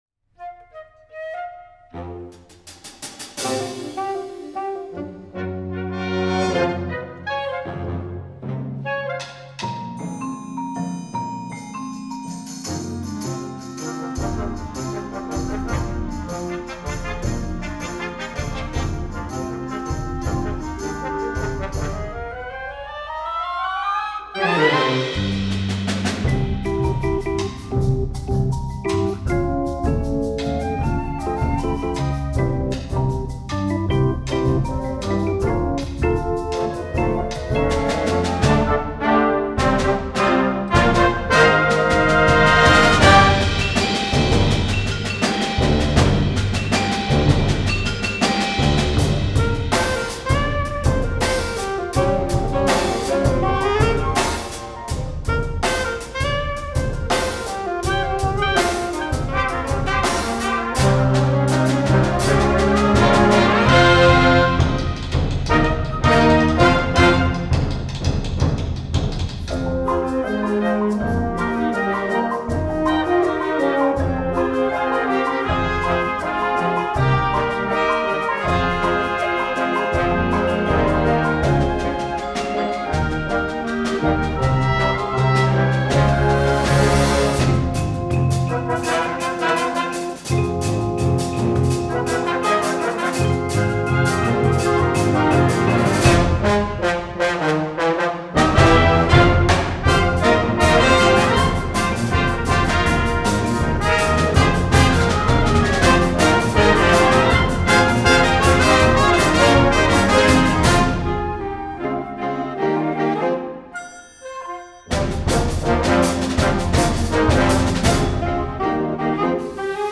Concert & Festival
Back to the Concert Band Page